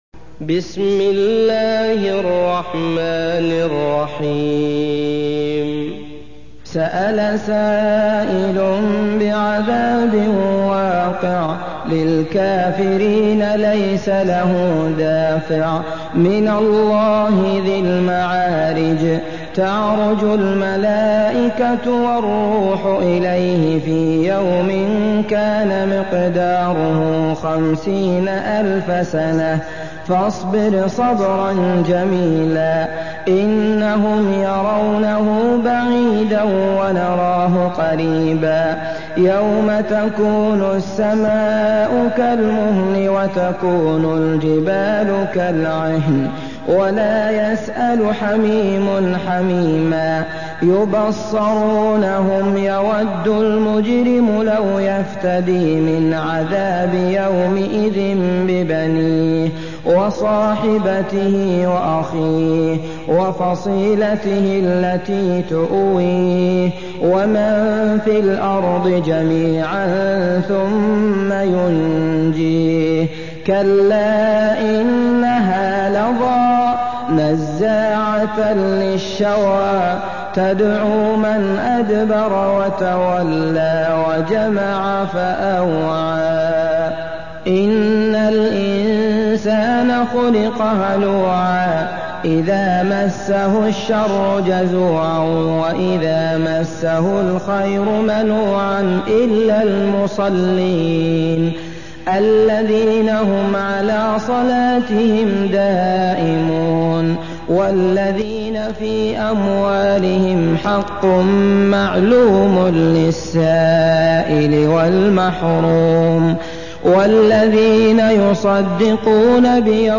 Surah Sequence تتابع السورة Download Surah حمّل السورة Reciting Murattalah Audio for 70. Surah Al-Ma'�rij سورة المعارج N.B *Surah Includes Al-Basmalah Reciters Sequents تتابع التلاوات Reciters Repeats تكرار التلاوات